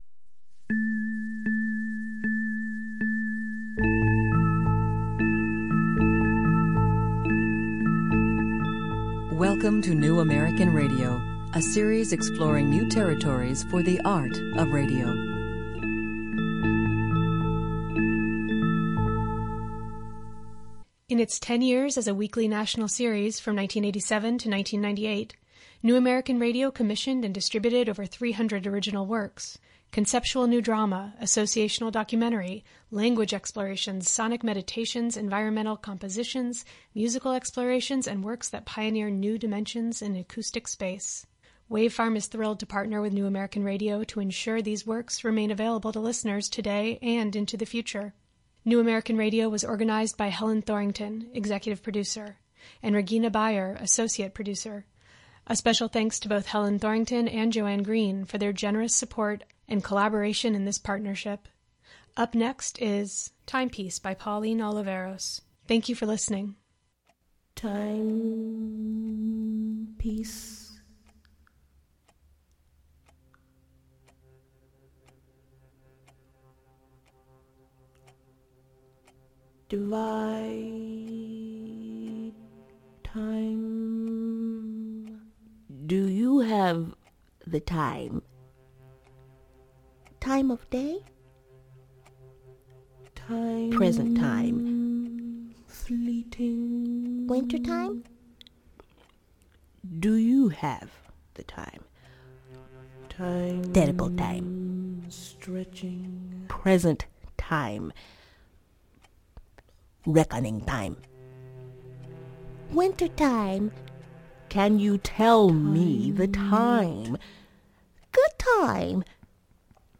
time-illusions evoked in the sound design and music
the feeling of time stretched and suspended, of time beyond